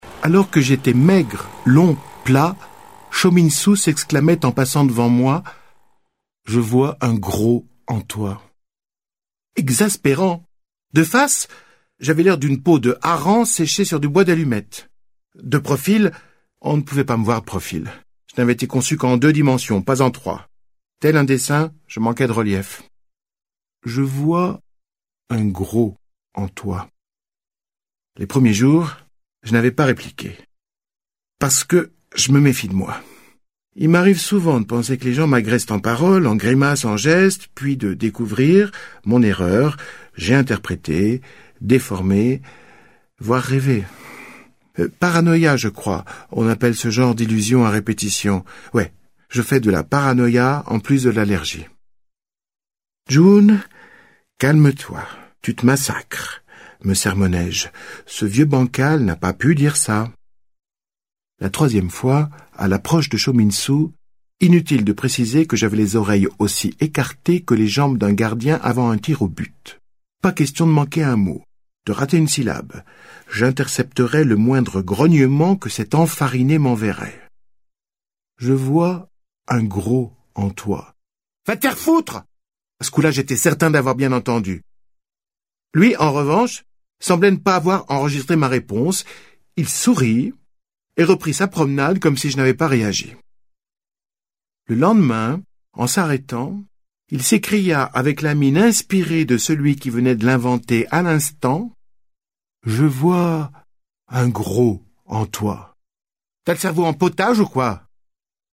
Lire un extrait - Le sumo qui ne pouvait pas grossir de Eric-Emmanuel Schmitt
L'émouvante lecture d'Eric-Emmanuel Schmitt fait passer un souffle d'optimisme et de bonheur, un moment d'éveil à partager.